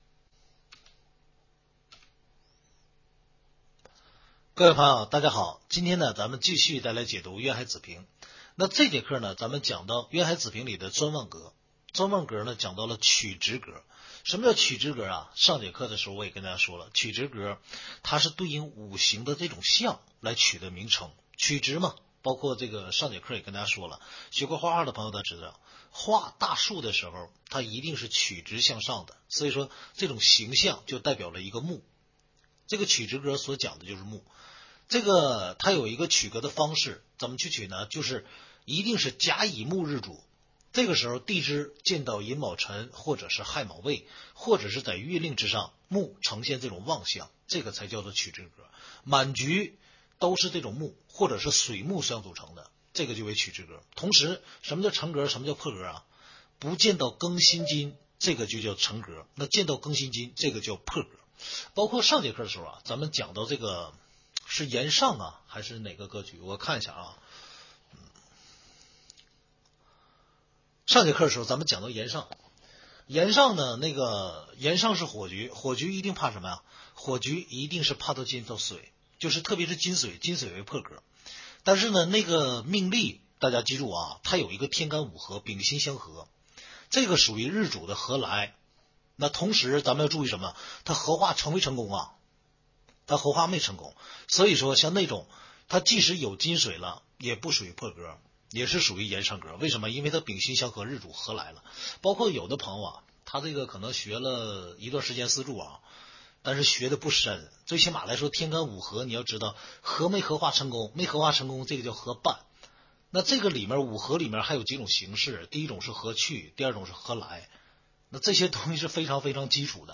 听书渊海子平白话文